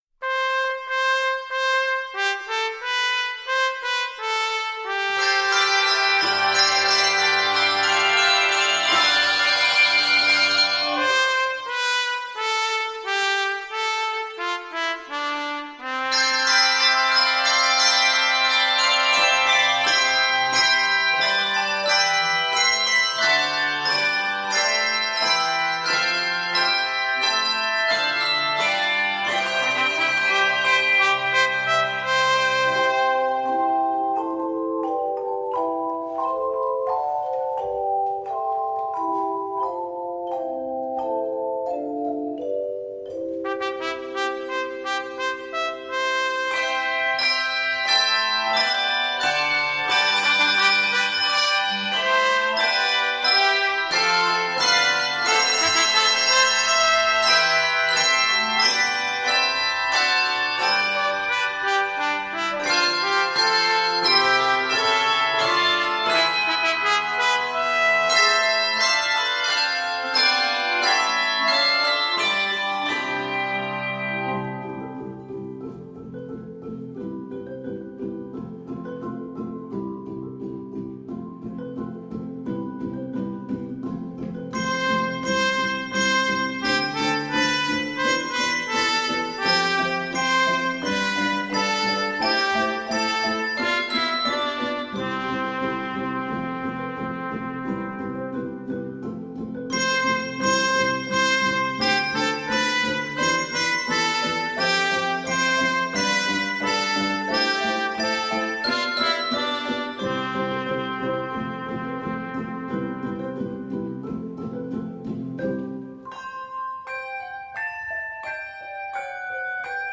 It is 89 measures and is scored in C Major.